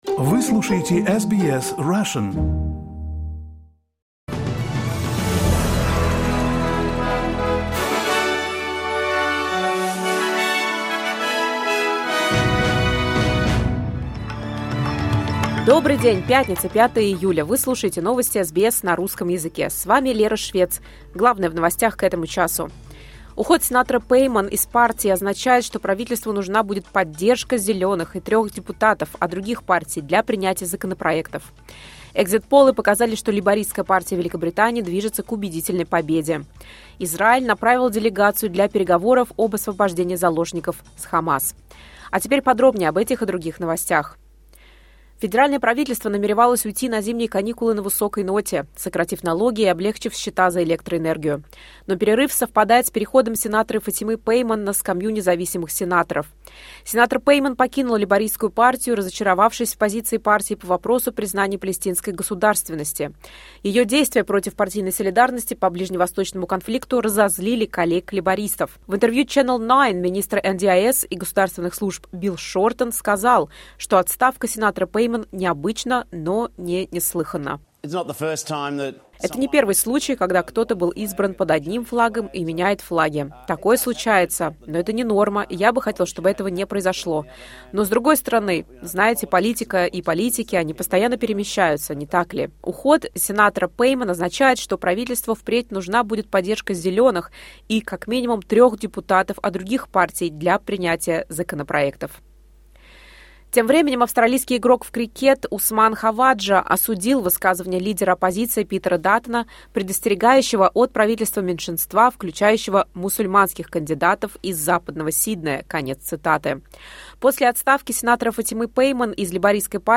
SBS News in Russian — 05.07.2024